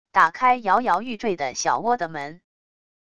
打开摇摇欲坠的小窝的门wav音频